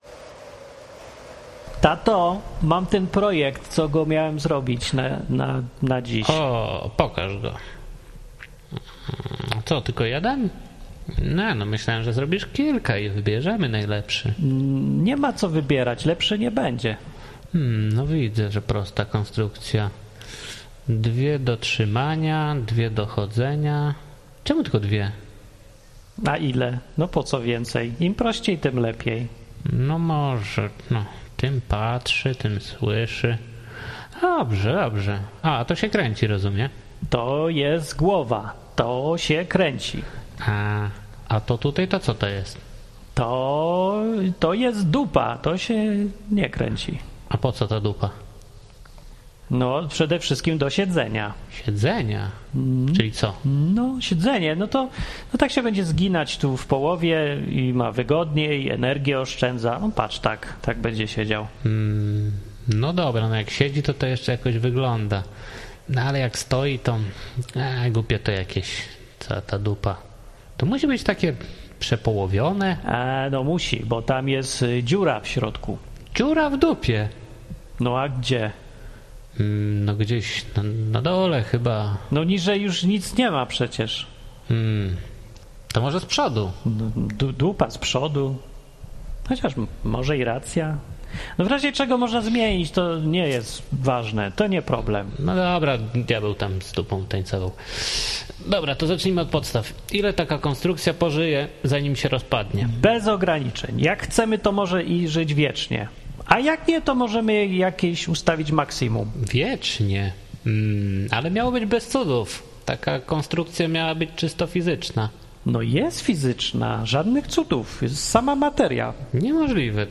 Niepublikowane nigdy wcześniej zapiski z ważnej narady w niebie. Tajna rozmowa na temat tajnych planów, która wiele wyjaśnia.